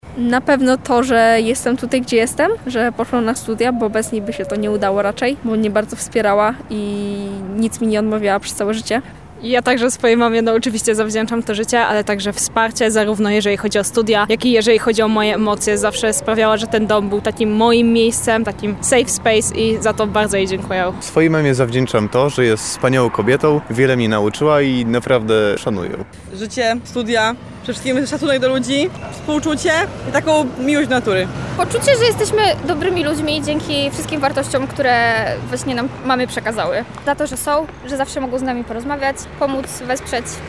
Z tej okazji zapytaliśmy mieszkańców Lublina, za co są wdzięczni swoim mamom:
Dzień Matki – sonda
dzien-matki-sonda.mp3